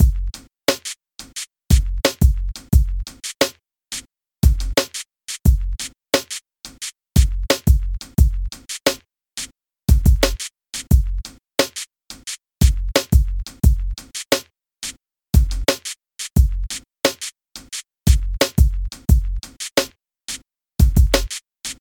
Hip-hop uses rhythmic music and chanted rhyming speech. It may also include beat sampling of bass lines and rhythmic beat-boxing.
hip-hop music.
mod12top5_content_hiphop.mp3